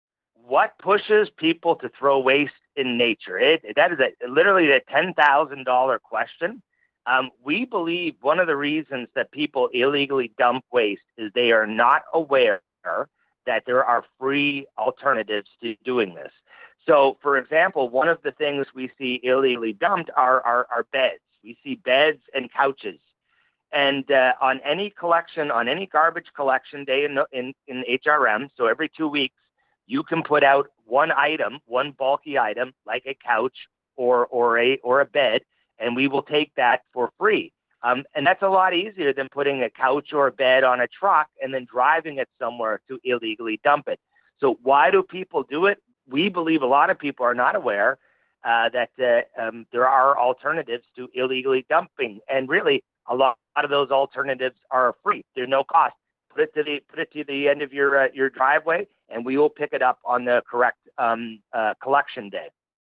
nous explique ce qu’il en est à l’occasion de l’entretien qu’il a accordé à OUI 98,5 FM